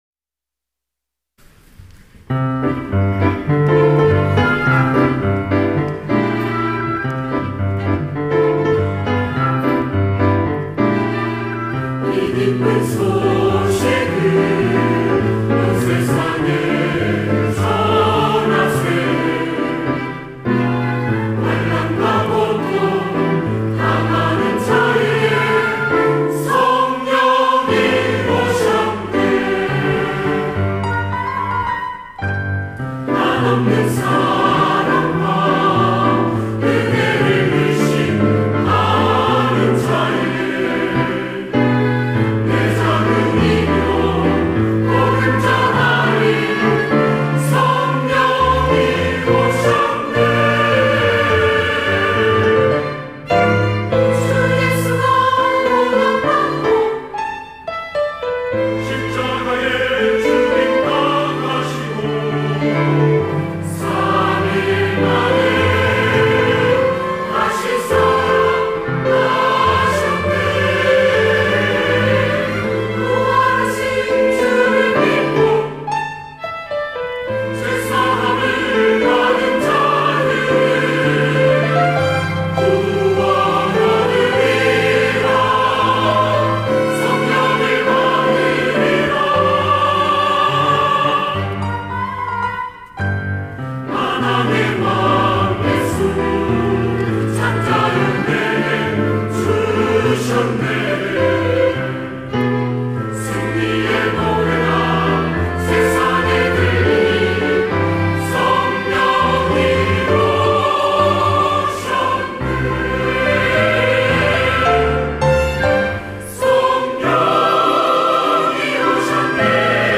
할렐루야(주일2부) - 이 기쁜 소식을
찬양대